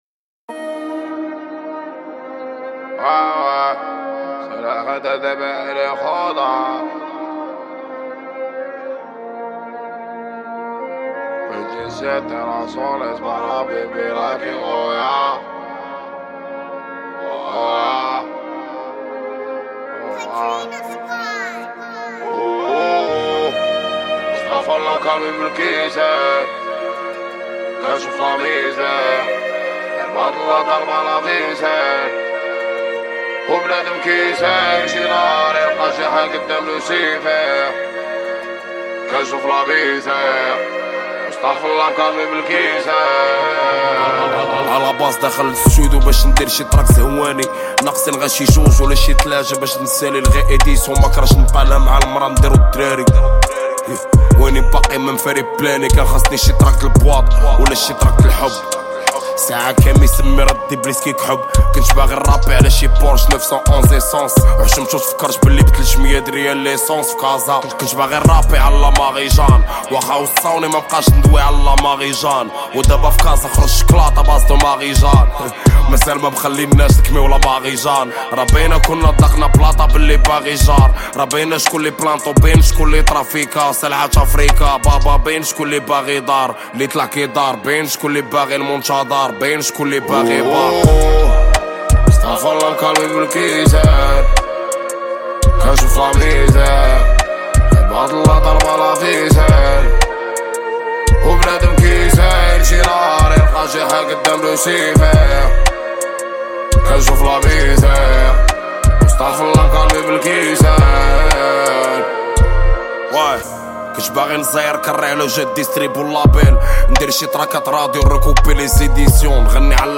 Genres : french rap, french r&b